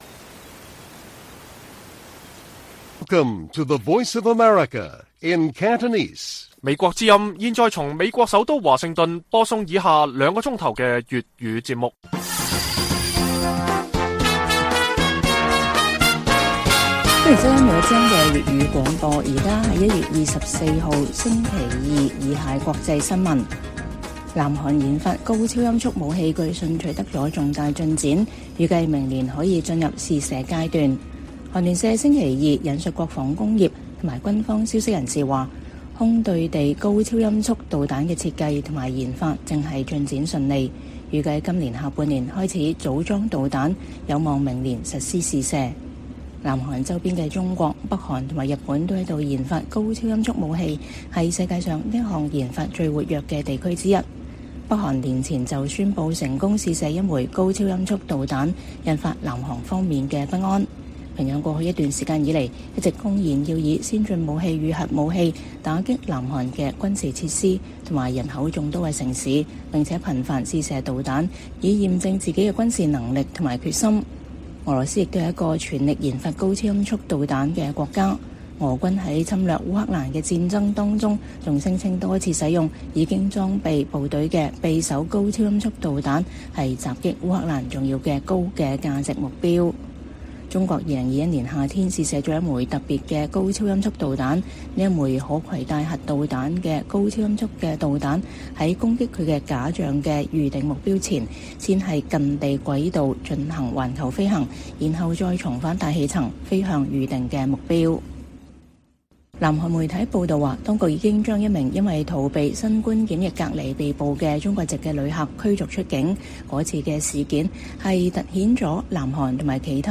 粵語新聞 晚上9-10點: 南韓研發高超音速武器據信取得重大進展